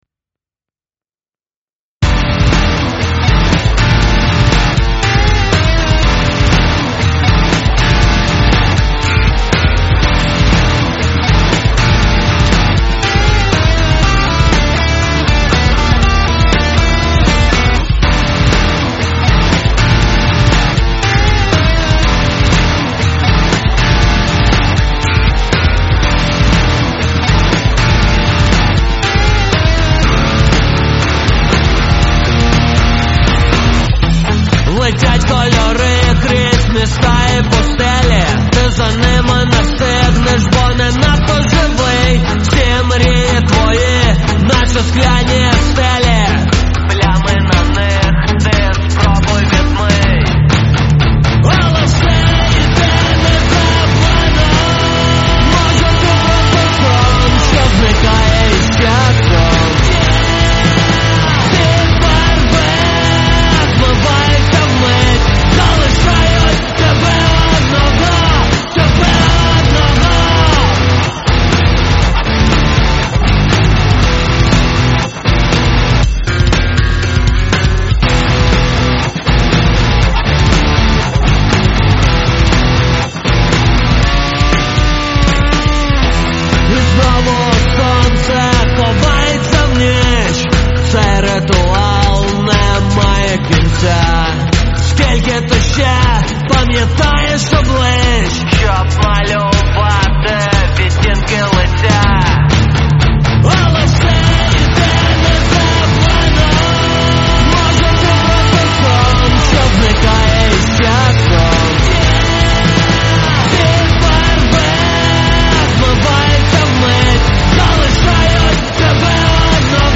Стиль: Гранж\Альтернатива